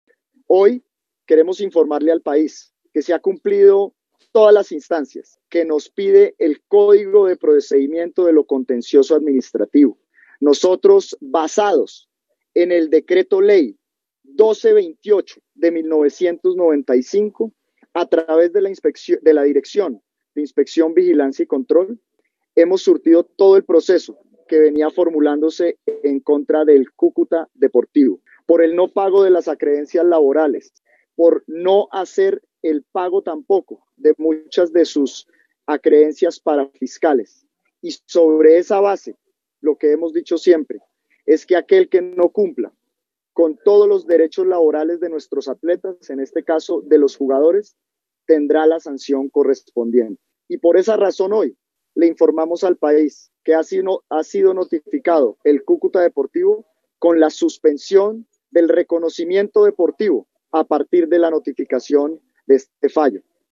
(Ernesto Lucena, ministro del Deporte)